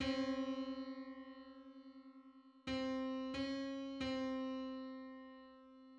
Just: 133/128 = 66.34 cents.
Public domain Public domain false false This media depicts a musical interval outside of a specific musical context.
Hundred-thirty-third_harmonic_on_C.mid.mp3